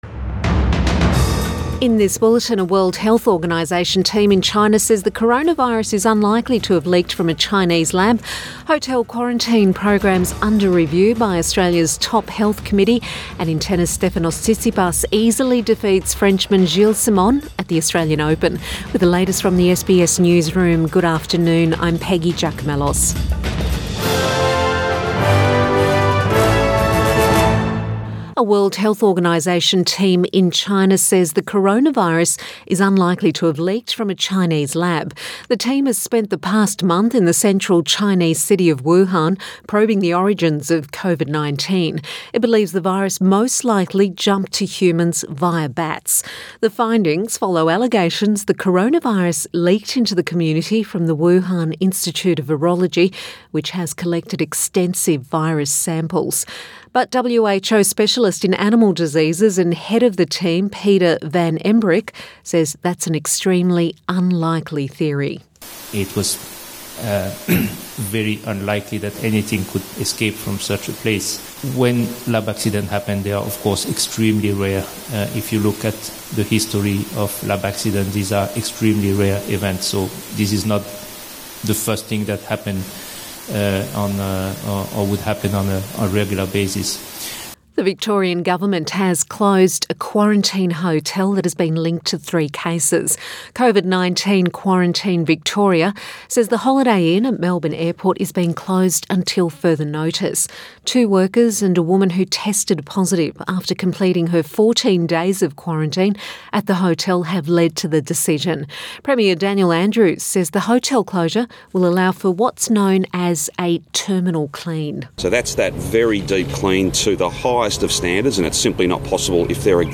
Midday bulletin 10 February 2021